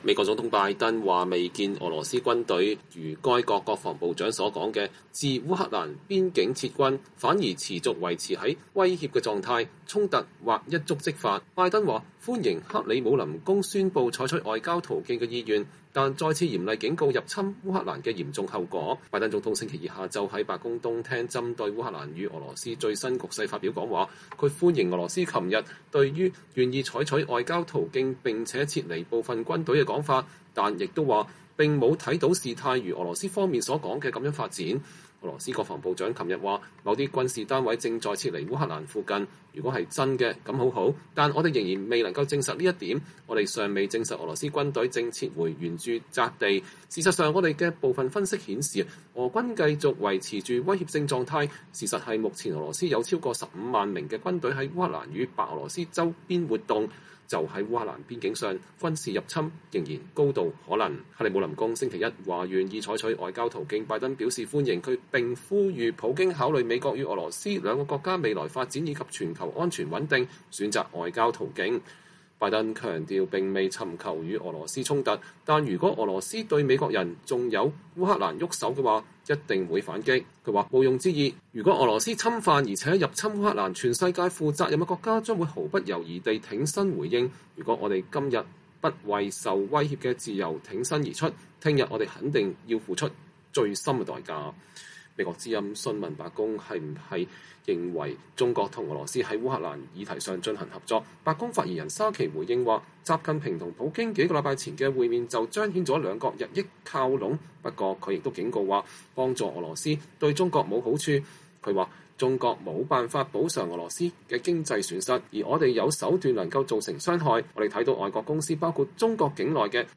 拜登總統週二下午在白宮東廳針對烏克蘭與俄羅斯最新局勢發表講話，他歡迎俄羅斯昨天對於願意採取外交途徑並且撤離部分軍隊的說法，但也說並沒有看到事態如俄羅斯方面所說的發展：“俄羅斯國防部長昨日說，某些軍事單位正在撤離烏克蘭附近。